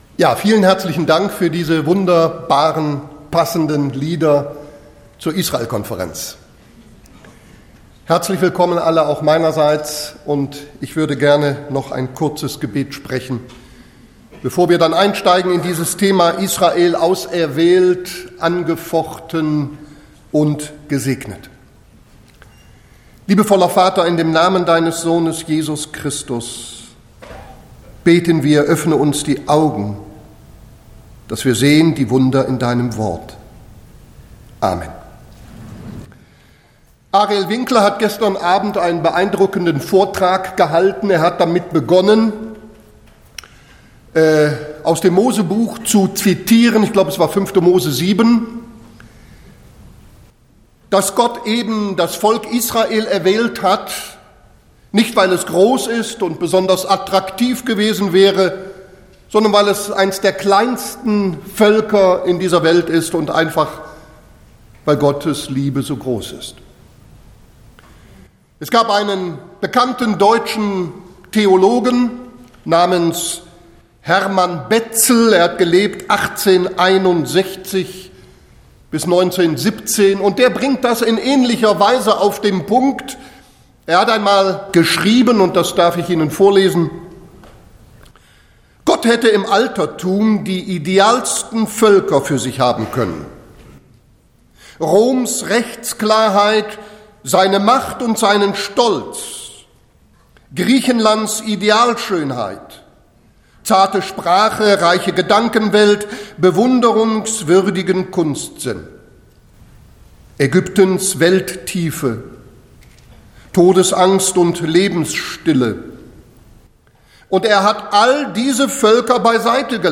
Botschaft